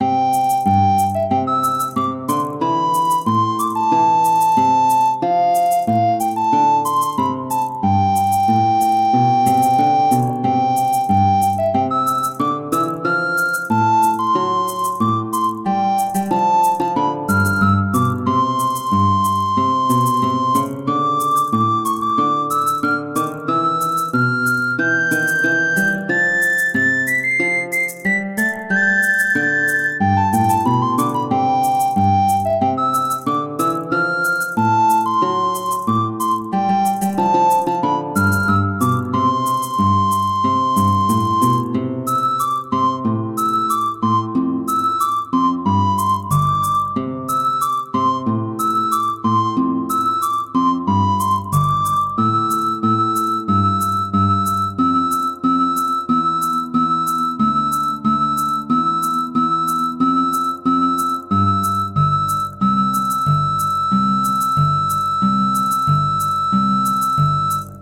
アコースティックギターの伴奏に乗って、オカリナがのどかなメロディーを奏でます。